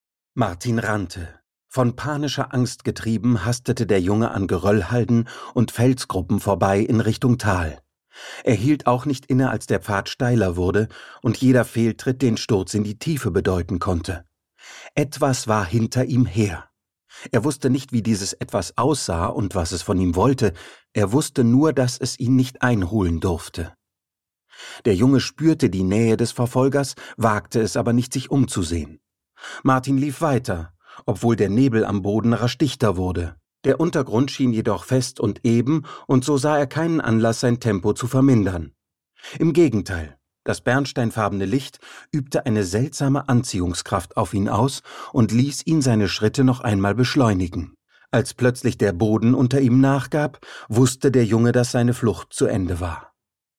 Mittel plus (35-65)
Audiobook (Hörbuch)